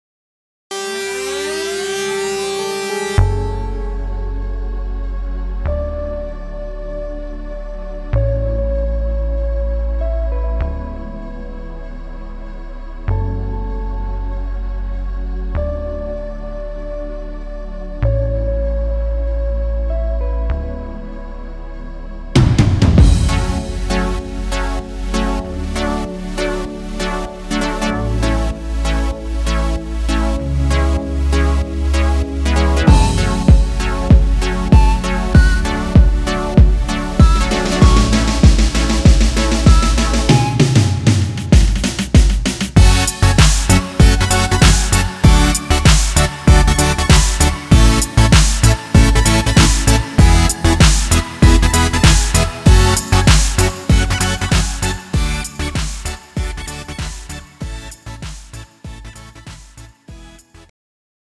음정 -1키
장르 pop 구분 Pro MR